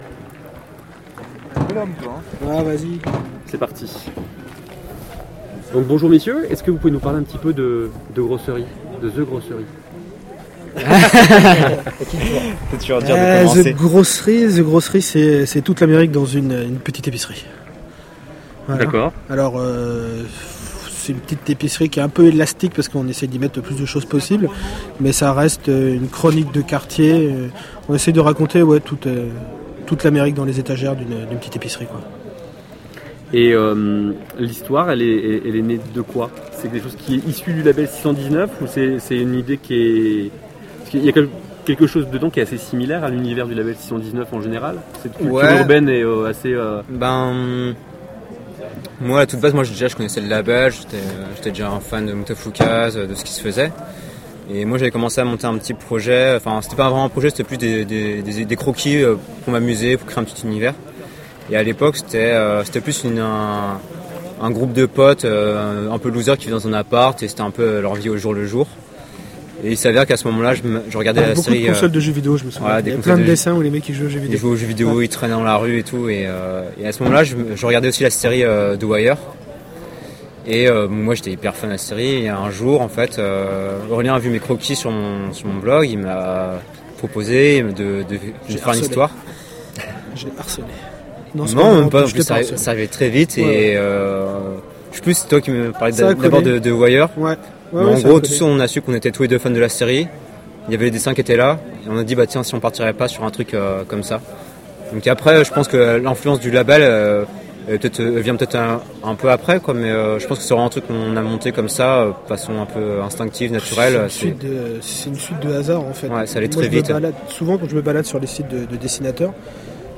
Une entrevue super agréable et détendue ou l’on a pu apprendre beaucoup de choses sur la genèse de la série et sur les projets a venir.